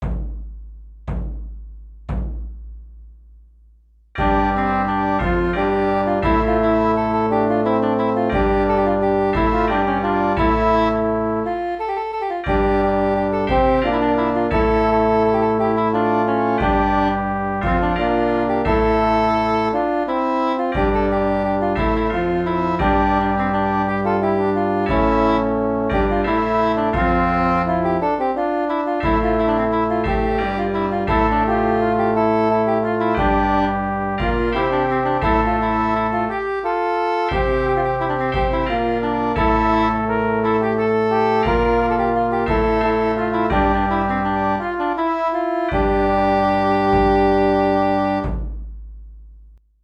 (triplum) |
004-triplum.mp3